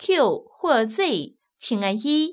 ivr-press_one_q_or_z.wav